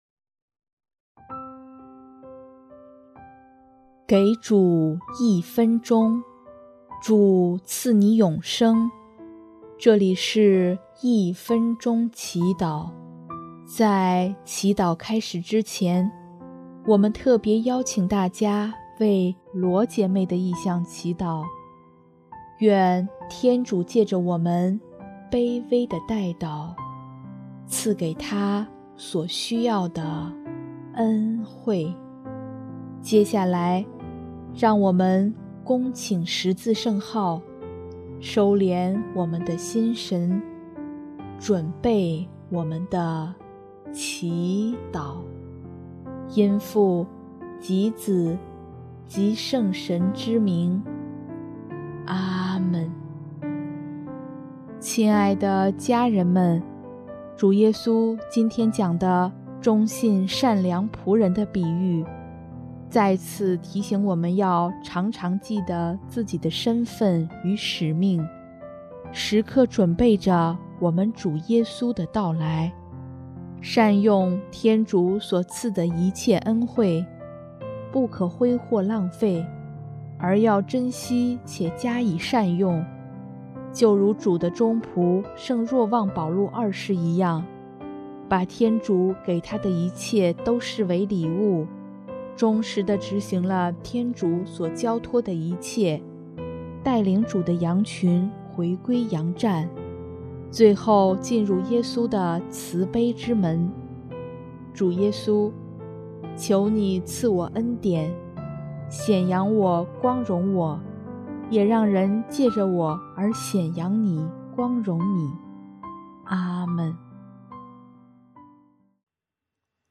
【一分钟祈祷】|10月22日 天主给的多，要的也多